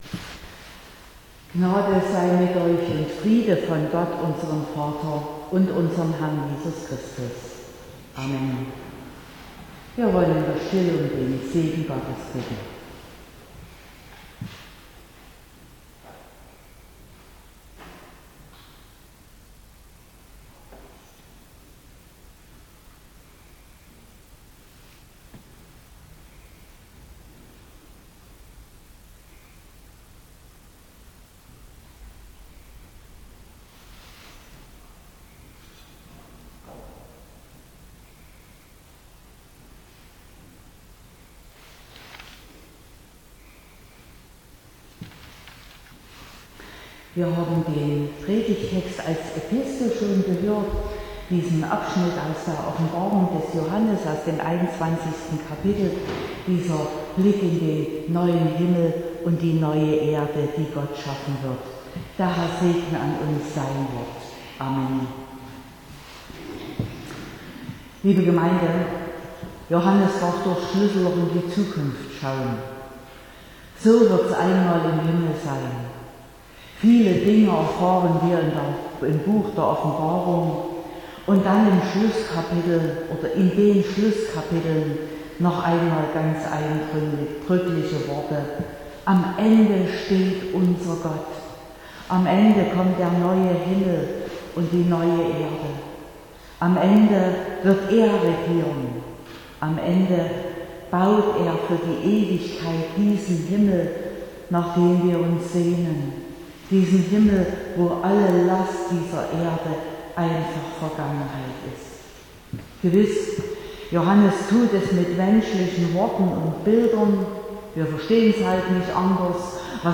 24.10.2021 – Gottesdienst
Predigt und Aufzeichnungen